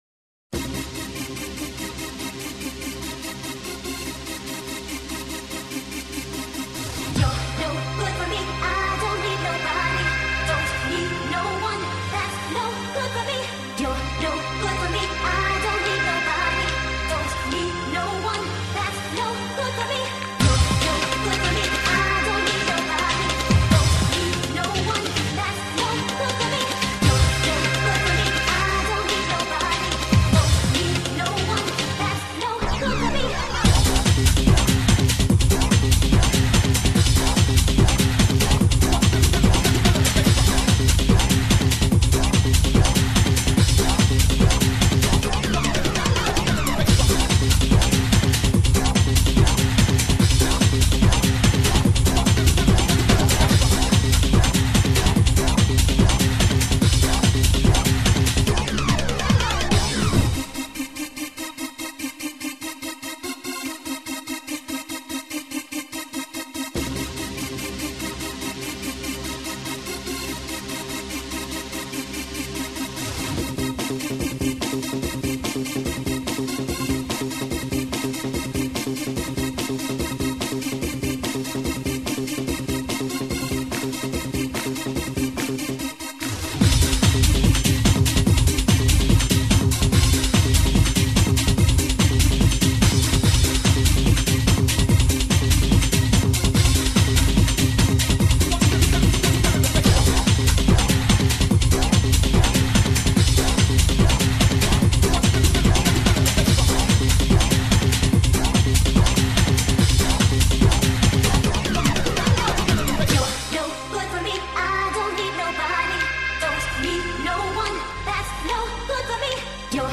Назад в ¤Super / Club / Dance¤